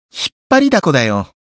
觉醒语音 太受欢迎了 引っ張りだこだよ 媒体文件:missionchara_voice_686.mp3